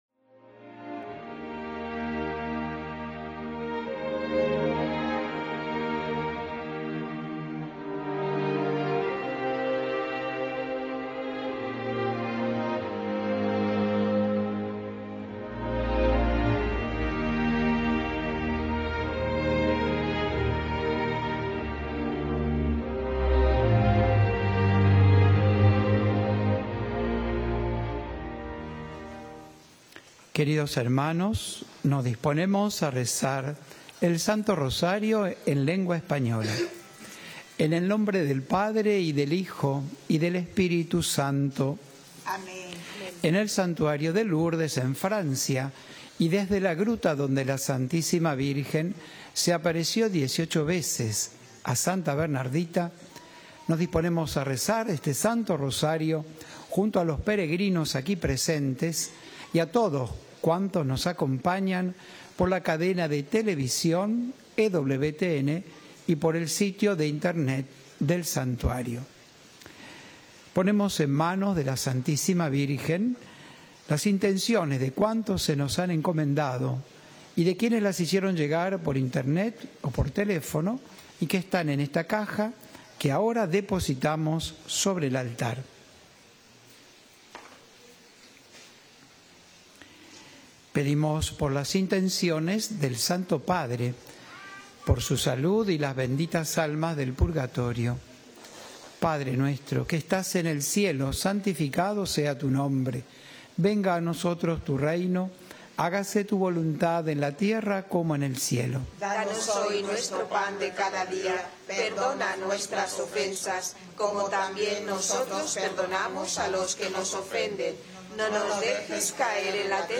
ROSARIO DESDE LOURDES